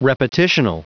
Prononciation du mot repetitional en anglais (fichier audio)
repetitional.wav